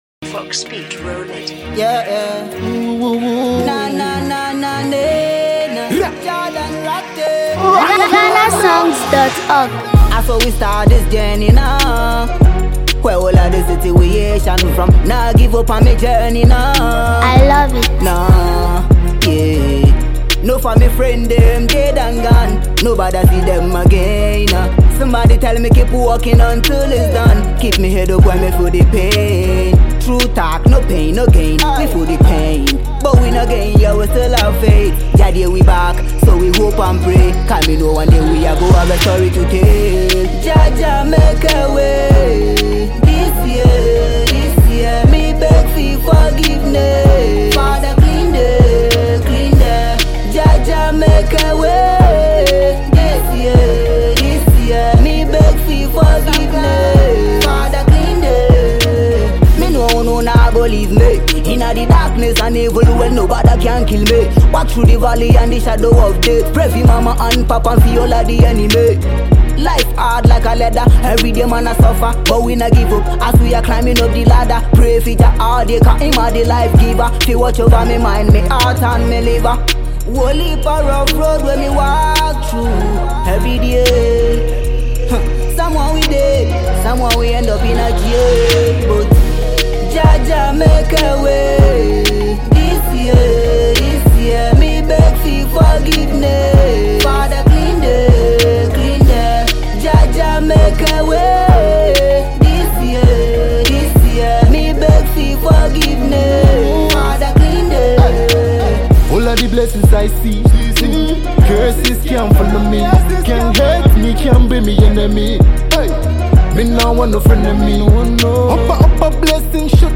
an anthem for dream chasers and hustlers
smooth, soulful touch